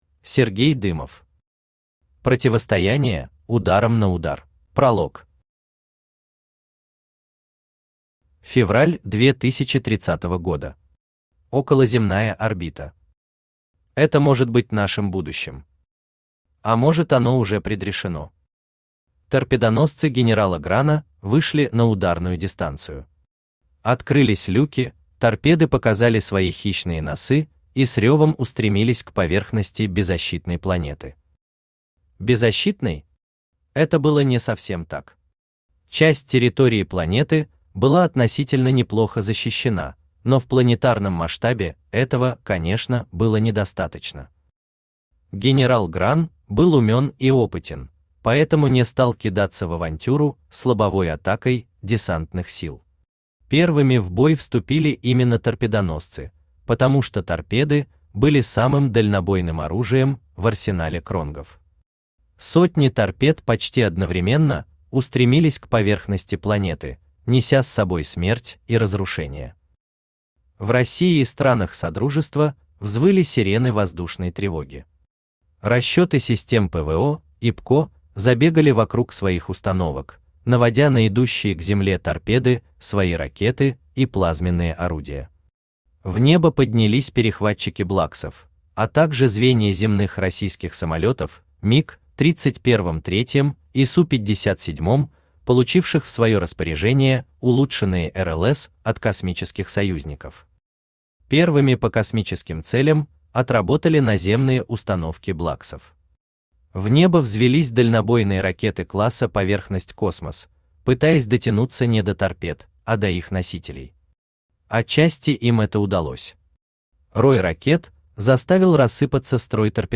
Аудиокнига Противостояние: Ударом на удар | Библиотека аудиокниг
Aудиокнига Противостояние: Ударом на удар Автор Сергей Дымов Читает аудиокнигу Авточтец ЛитРес.